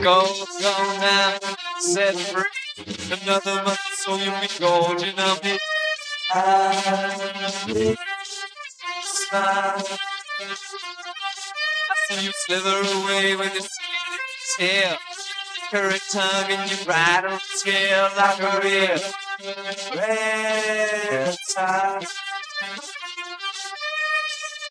I truly wished this would’ve turned out great as no official instrumental exists, but I can’t exactly fault the algorithms for not being able to track the vocals all that well, as this track has a lot of guitars and other stuff going on.